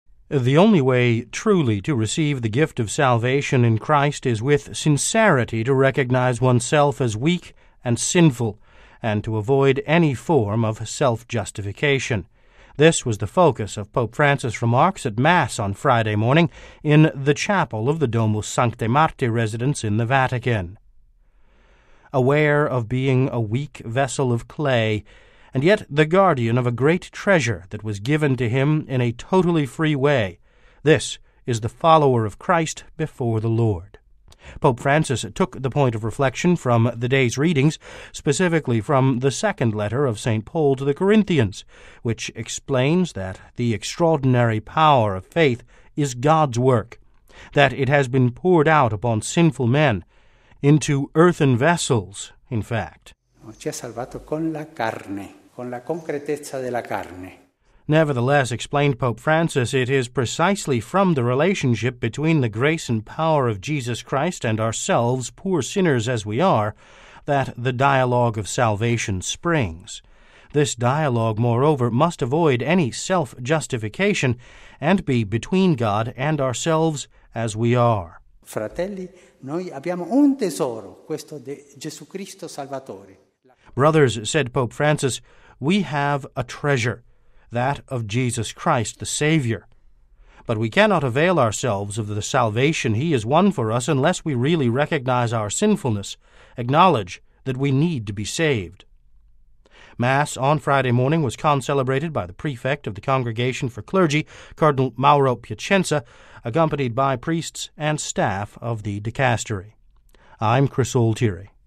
Pope Francis: Friday Mass at Santa Marta
(Vatican Radio) The only way truly to receive the gift of salvation in Christ is with sincerity to recognize oneself as weak and sinful, and to avoid any form of self-justification. This was the focus of Pope Francis’ remarks at Mass Friday morning in the chapel of the Domus Sanctae Marthae residence in the Vatican.